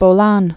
(bō-län)